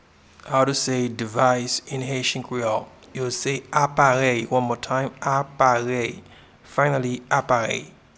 Pronunciation and Transcript:
Device-in-Haitian-Creole-Aparey.mp3